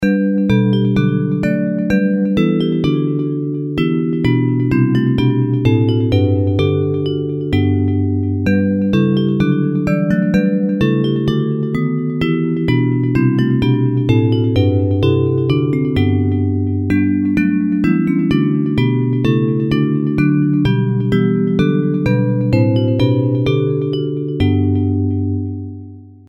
Bells Version